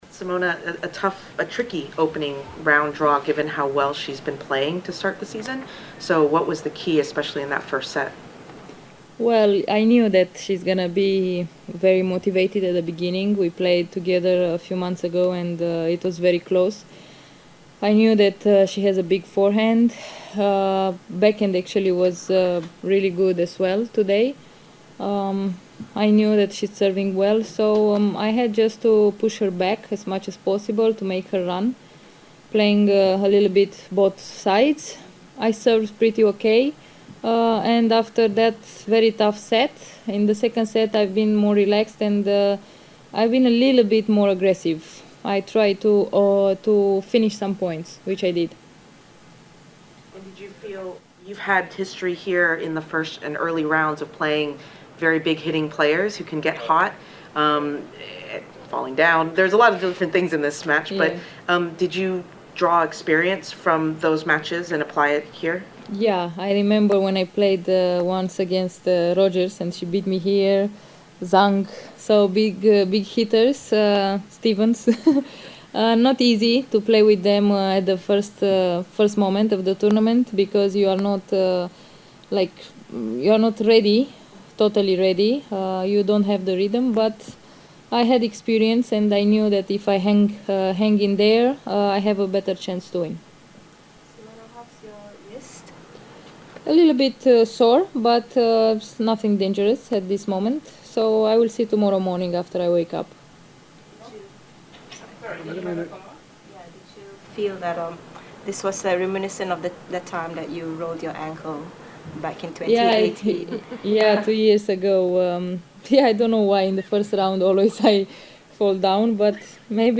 Simona Halep press conference after winning 7-6, 6-1 against Jennifer Brady, in the first round at the Australian Open